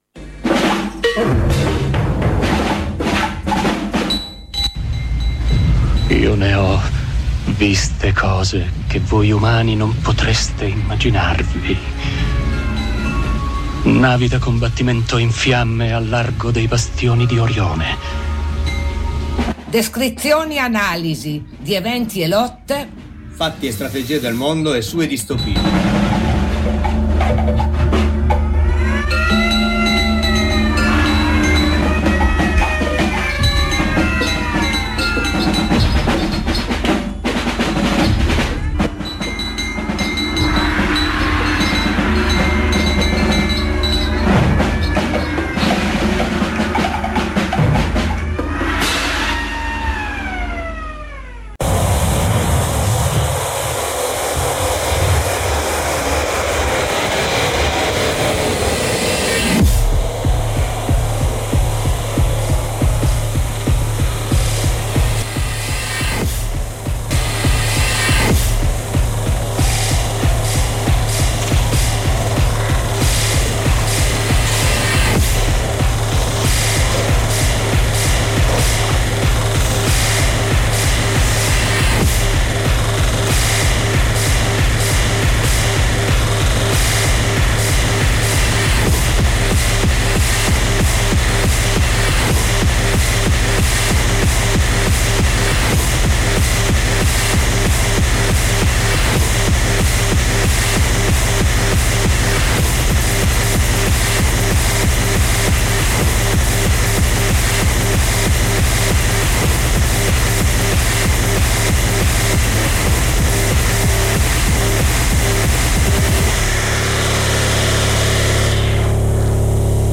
Bastioni di Orione ,trasmissione nomade di Radio black out che si insinua nei buchi del palinsesto per raccontare dei fatti del mondo ,in questa puntanta parliamo della guerra in Tigray e delle manifestazioni in Colombia .